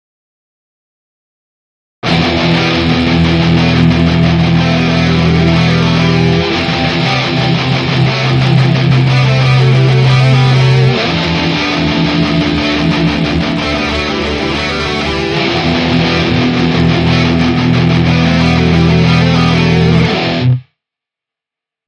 Вниз  Играем на гитаре